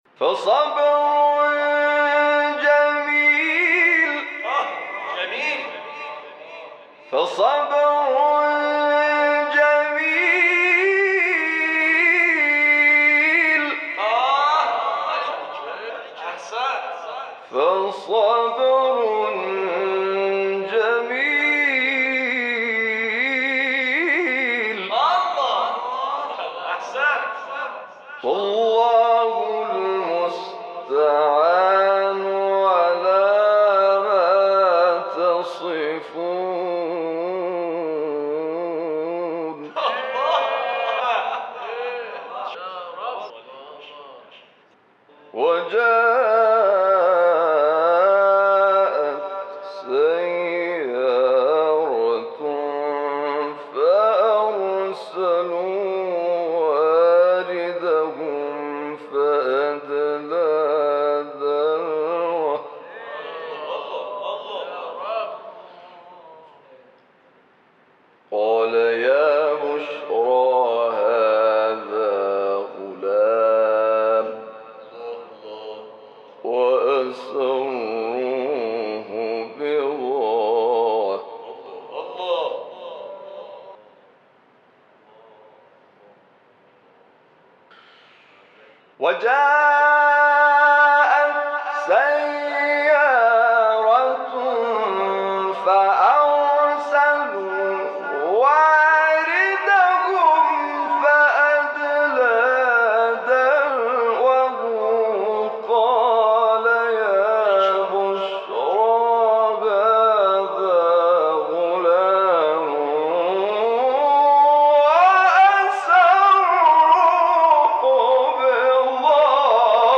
گروه شبکه اجتماعی: جدیدترین نغمات صوتی از تلاوت‌ قاریان ممتاز کشور را می‌شنوید.